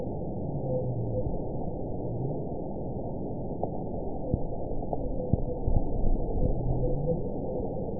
event 919288 date 12/29/23 time 22:01:23 GMT (1 year, 11 months ago) score 8.60 location TSS-AB05 detected by nrw target species NRW annotations +NRW Spectrogram: Frequency (kHz) vs. Time (s) audio not available .wav